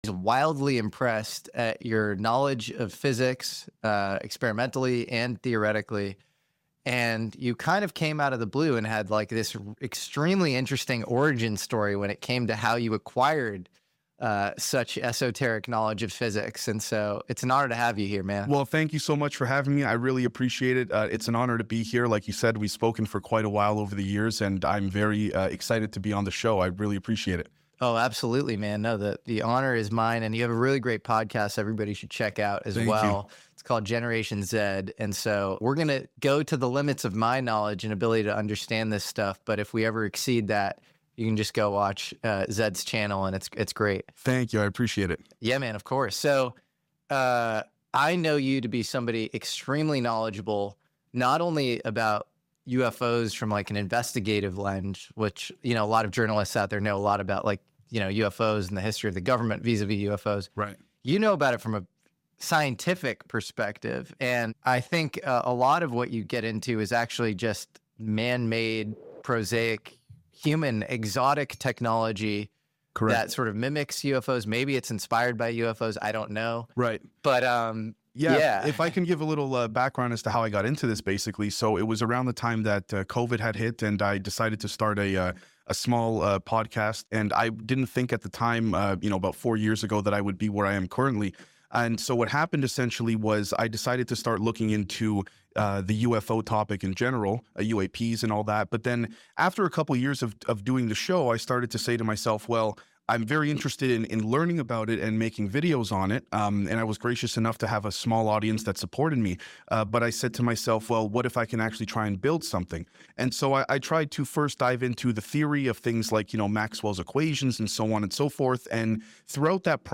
Long but interesting interview about sound effects free download